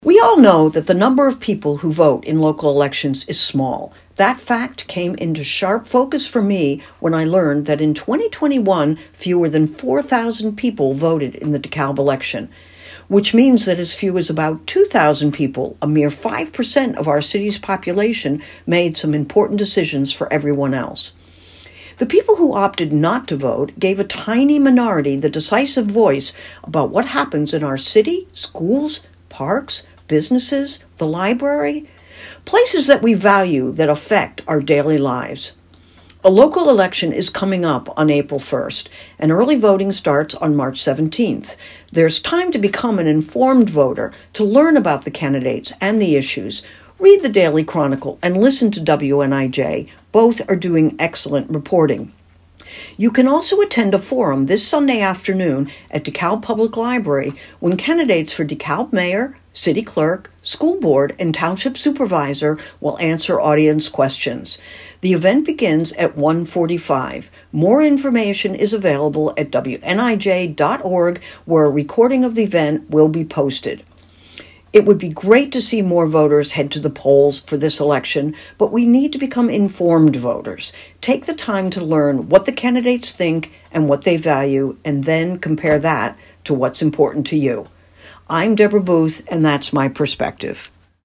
Perspectives are commentaries produced by and for WNIJ listeners, from a panel of regular contributors and guests.